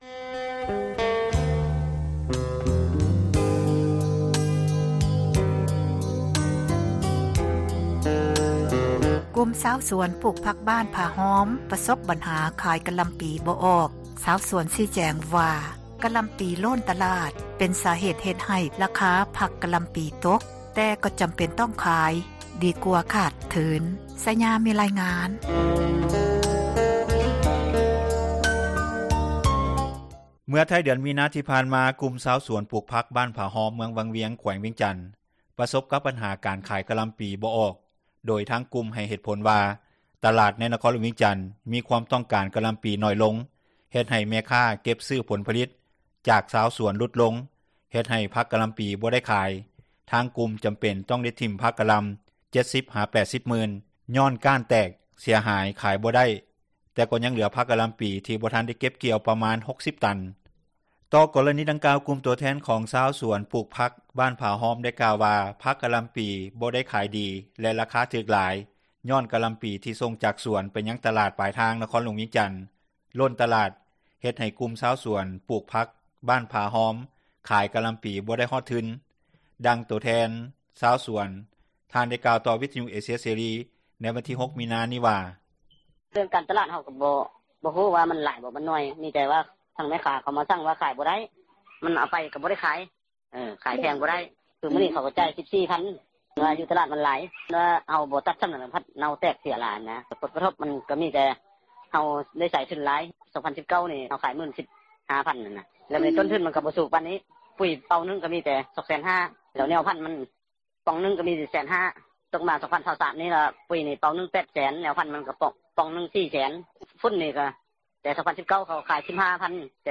ດັ່ງໂຕແທນຊາວສວນ ທ່ານໄດ້ກ່າວຕໍ່ວິທຍຸເອເຊັຍເສຣີ ໃນວັນທີ 06 ມີນານີ້ວ່າ:
ດັ່ງເຈົ້າໜ້າທີ່ແຂວງວຽງຈັນ ທ່ານໄດ້ກ່າວວ່າ: